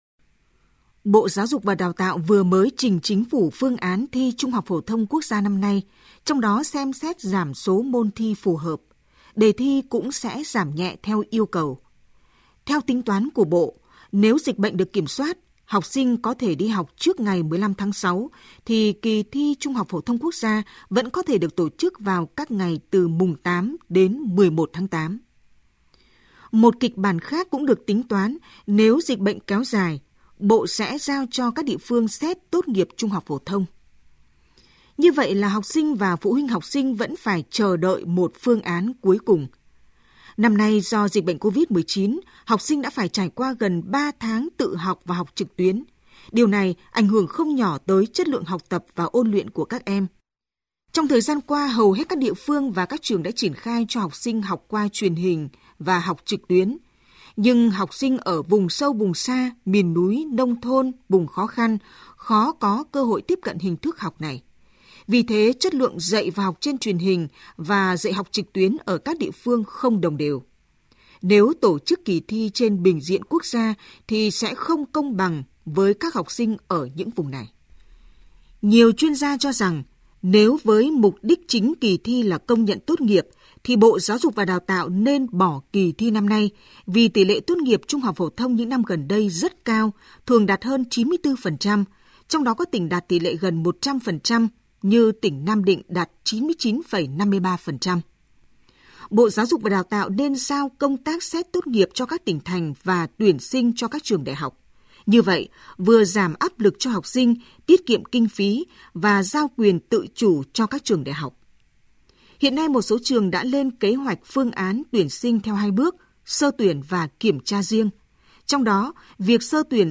THỜI SỰ Bình luận VOV1